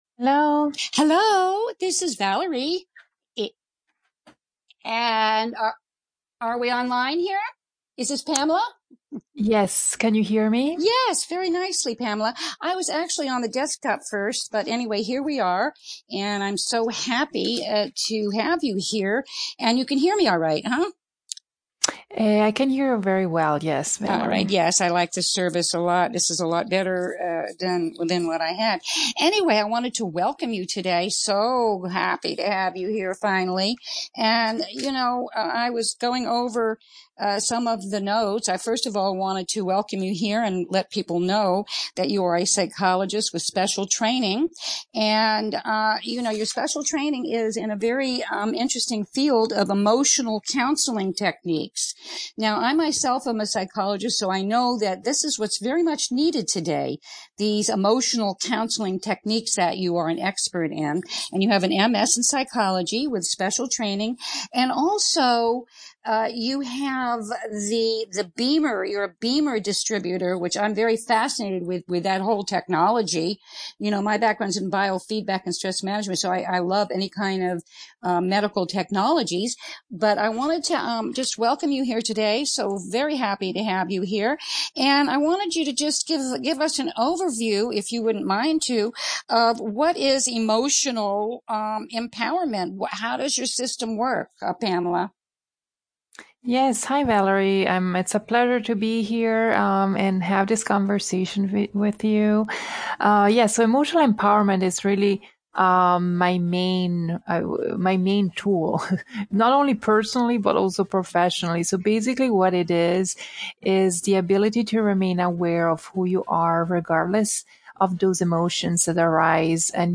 Click HERE to listen to our conversation about emotional empowerment.